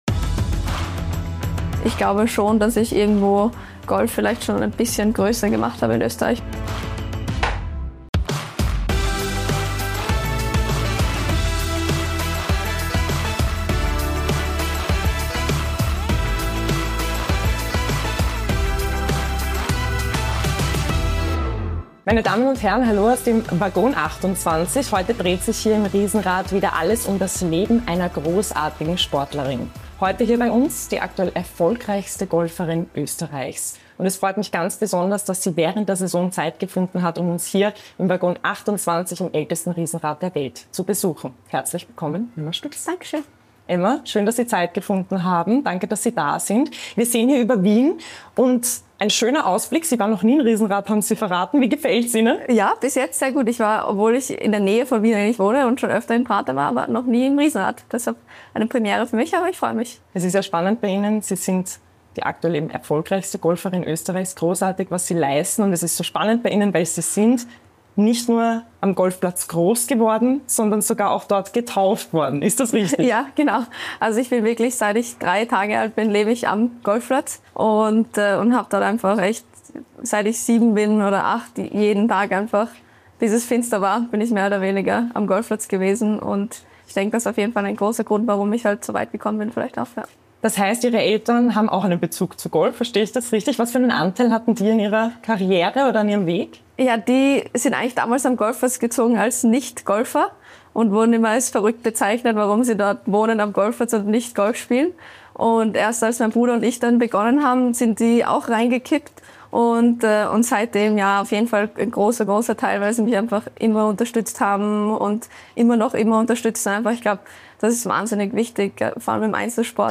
Talk Format